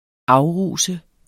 Udtale [ -ˌʁuˀsə ]